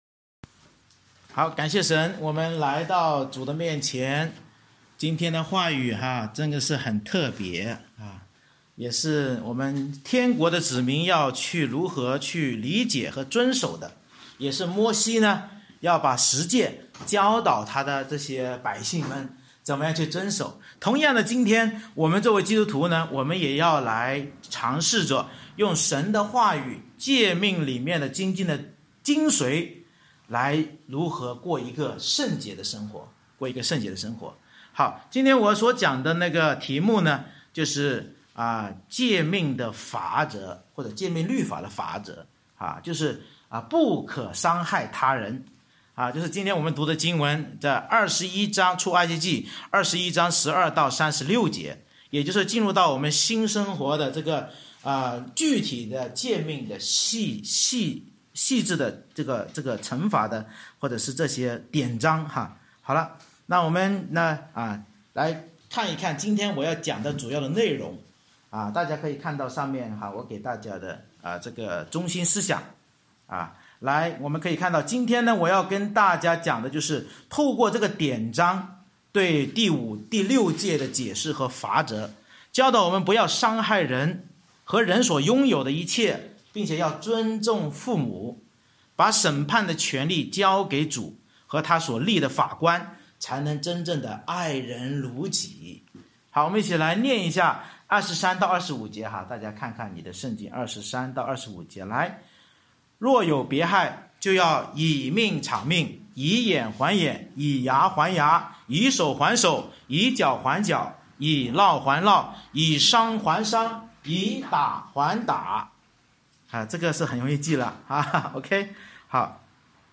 《出埃及记》讲道系列
出埃及记21：12-36 Service Type: 主日崇拜 Bible Text